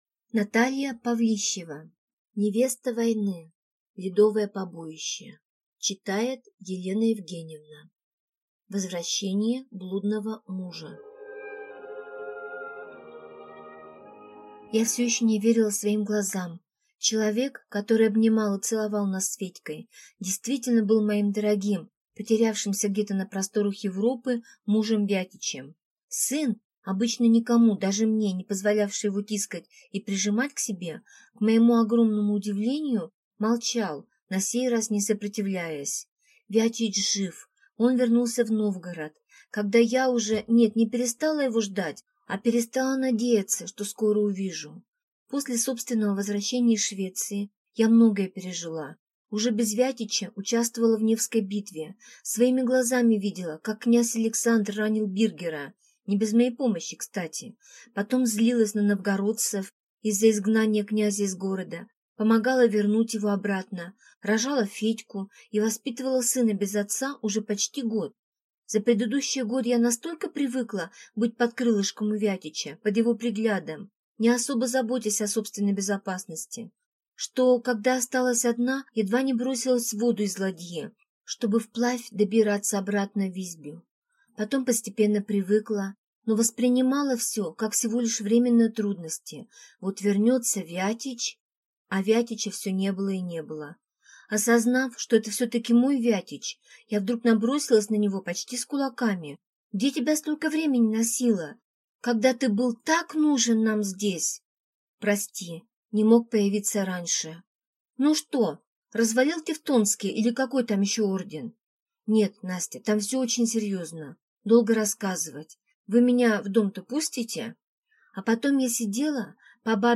Аудиокнига Ледовое побоище | Библиотека аудиокниг